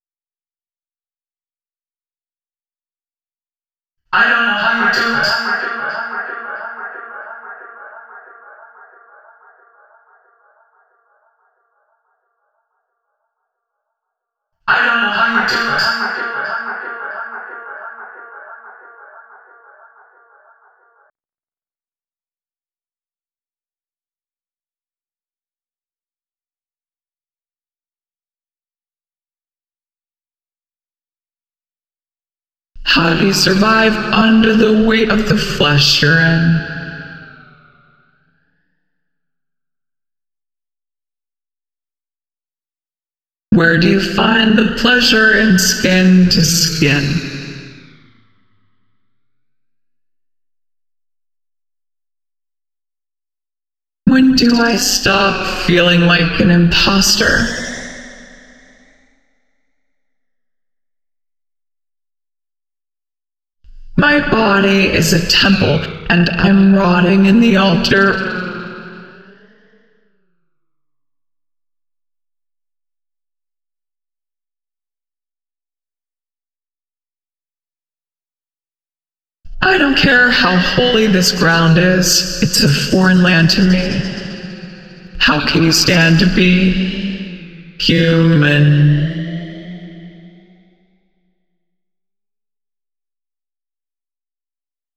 I used an old fashioned Text-to-Speech-to-Autotune method for the vocals, which maybe compliments the "I don't feel human" theme?
Glitchy and lot of interesting textures, a bit disconcerting, especially the lyrics and the uncanny valley robotic voice adds to that
idk how u do it VOX.flac